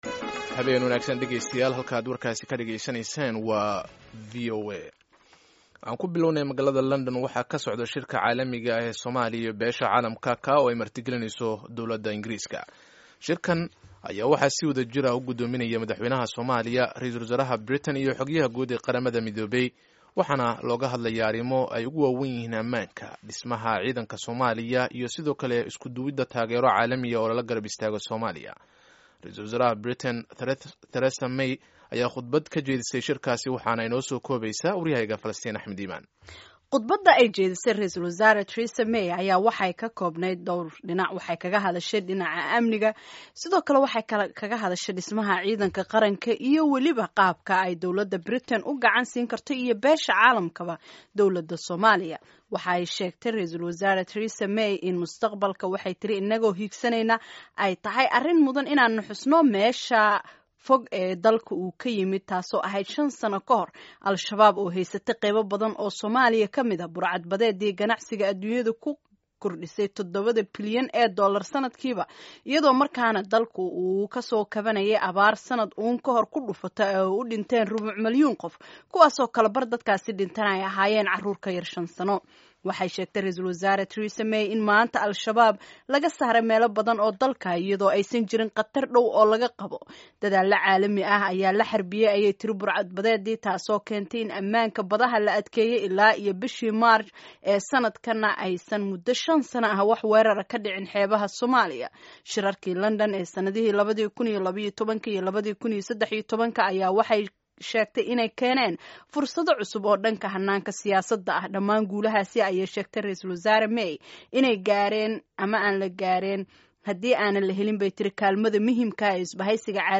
Warbixin